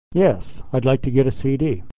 Unstressed 'your, you're' is reduced = /yər/